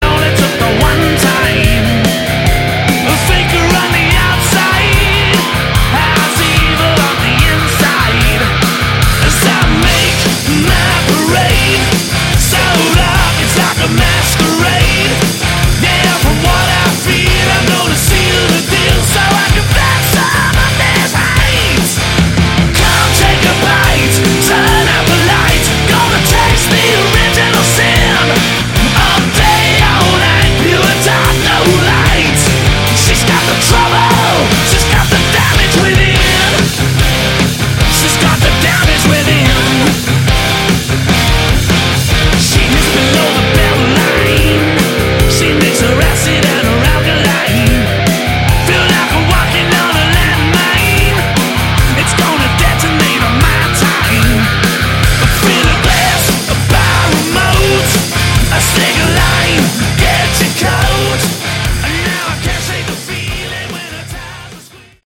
Category: Hard Rock
vocals, bass
guitars
drums